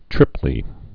(trĭplē)